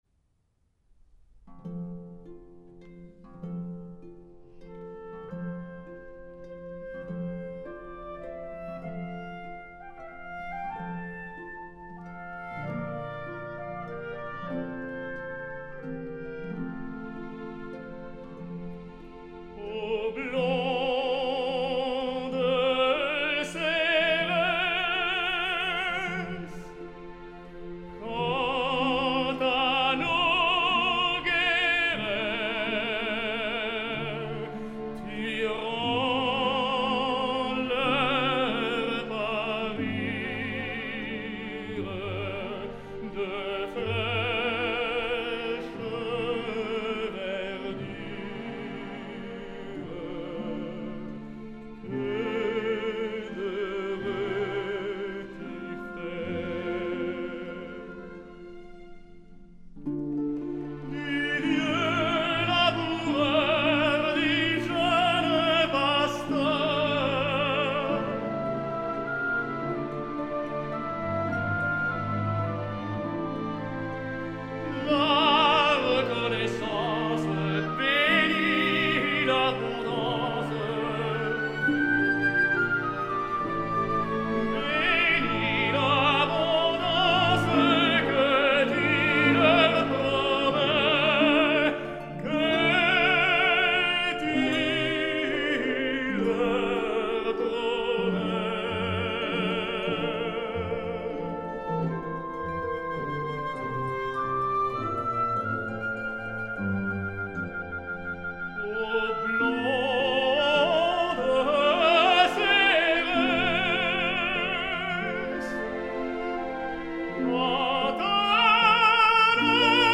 Sobre un acompanyament elegíac i camperol, iniciat per uns arpegis evocadors del arpa solista, Iopas, tenor líric, desplega la bellíssima melodia, amb un acompanyament deliciós.
La darrera versió, no és una gravació de teatre, prové d’un magnífic enregistrament dedicat íntegrament a les àries per a tenor en les obres d’Hector Berlioz i que té per protagonistes al tenor francès  Roberto Alagna i al director Bertrand de Billy, amb les col·laboracions especials de Angela Gheorghiu, que canta la Marguerite de la Damnation i l’actor Gerard Depardieu en una escena de Lélio.
La versió d’un Roberto Alagna en plena forma, sorprèn amb una veu molt més carnosa que la dels altres tres, més lírics lleugers i per una dicció, com sempre claríssima. És un Iopas luxós i romàntic.